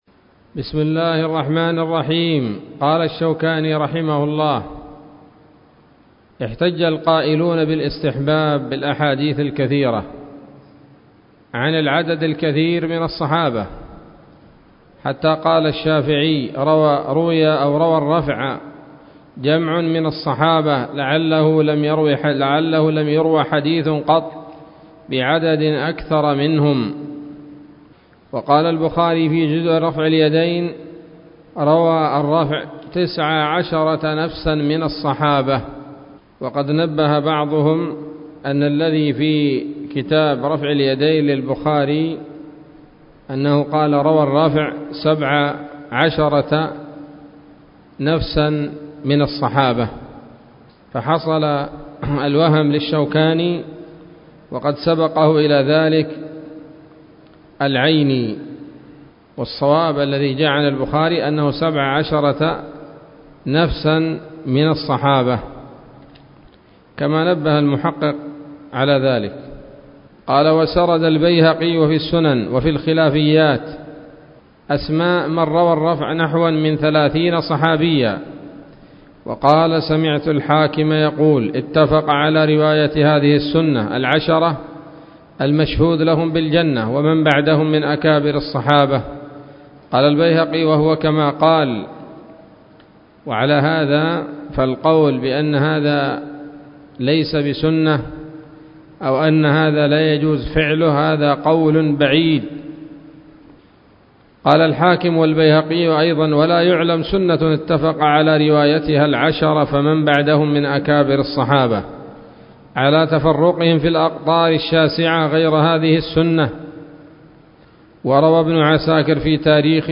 الدرس الخامس من أبواب صفة الصلاة من نيل الأوطار